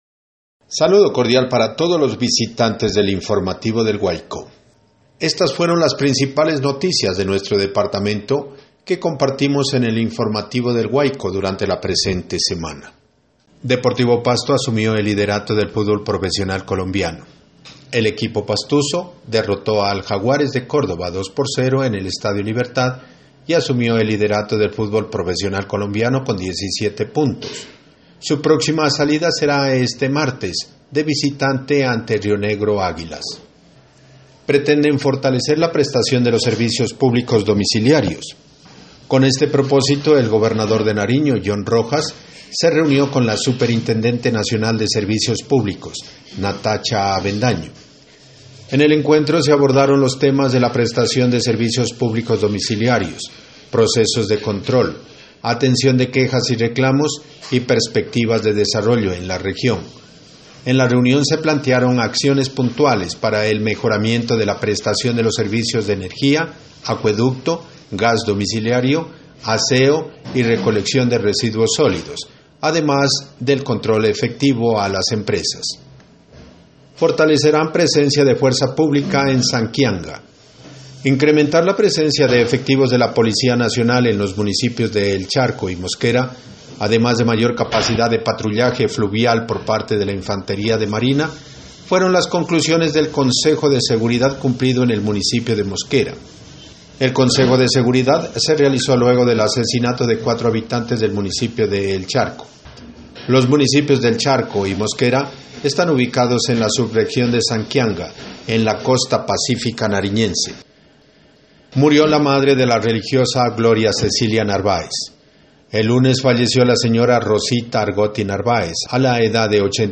Resumen semanal de noticias (audio 2)